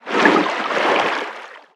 Sfx_creature_featherfish_swim_slow_05.ogg